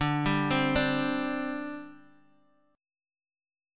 Listen to D7sus4 strummed